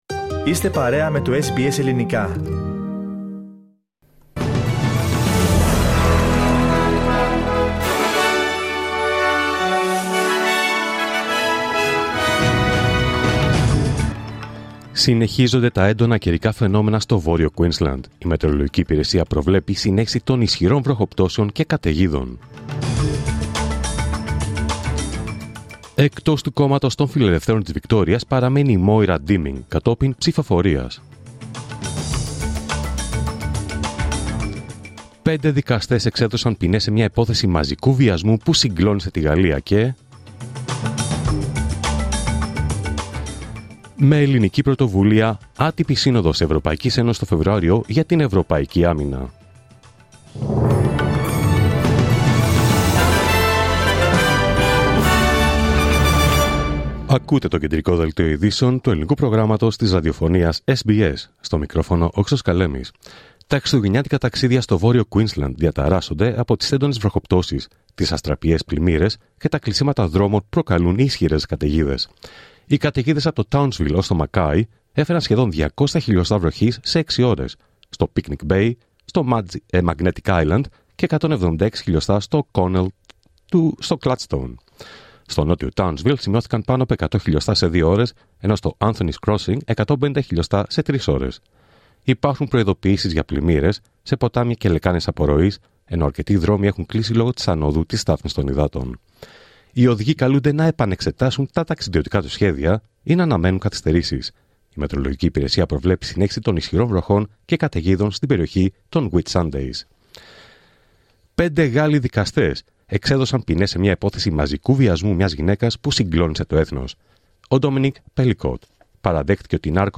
Δελτίο Ειδήσεων Παρασκευή 20 Δεκέμβριου 2024